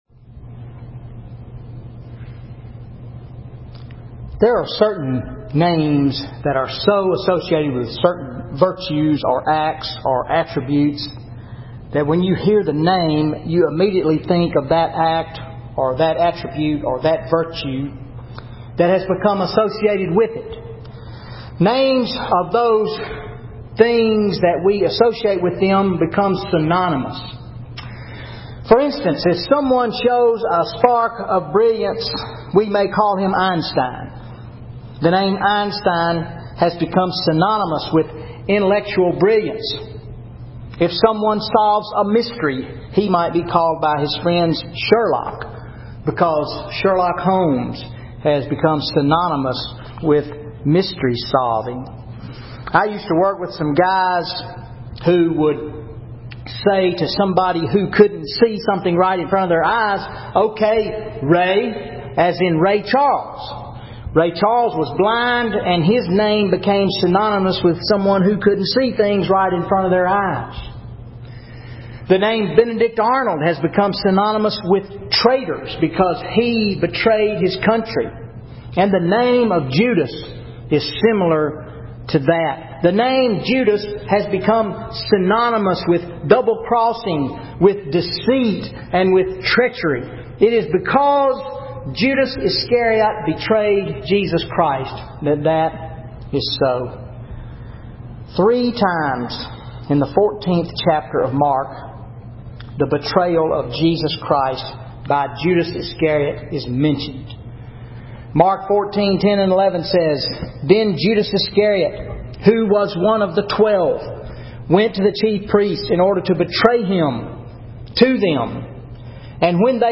Sunday Sermon July 14, 2013 Mark 14:27-52 The Betrayal of Jesus